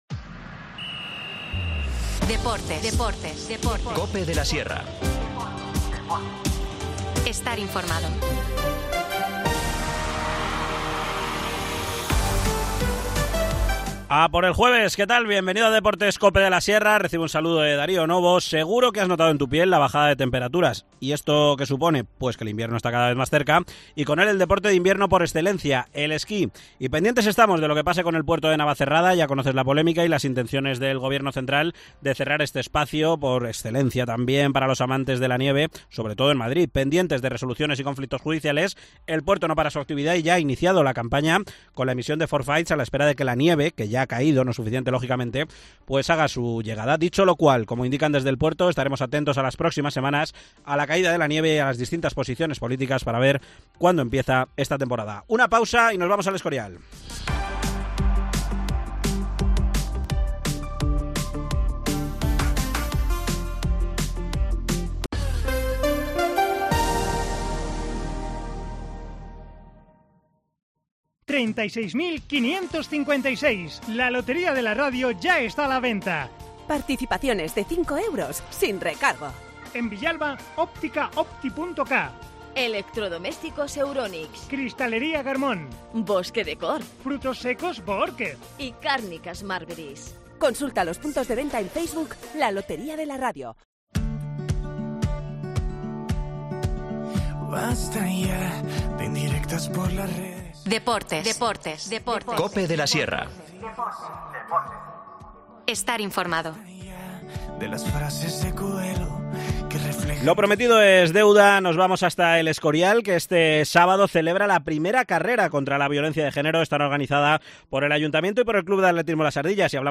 Deportes local